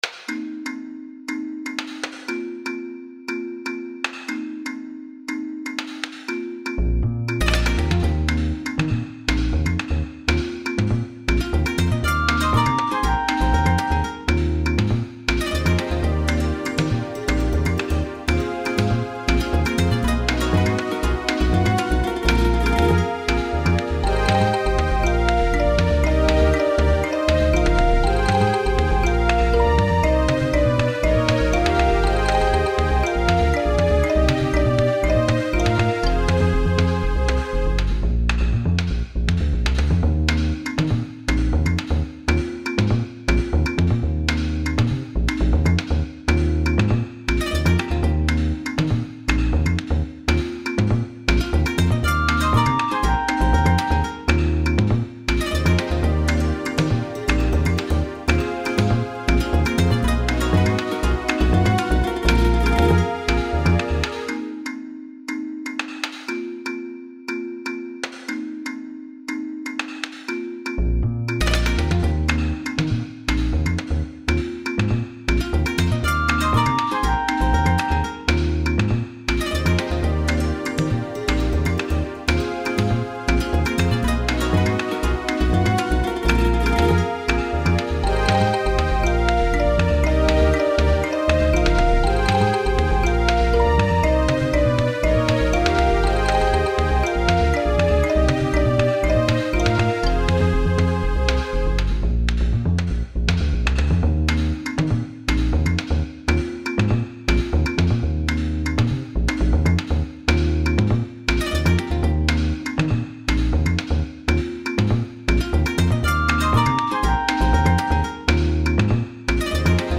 BGM
スローテンポロング民族